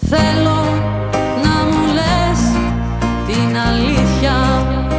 Με μοντέρνες ενορχηστρώσεις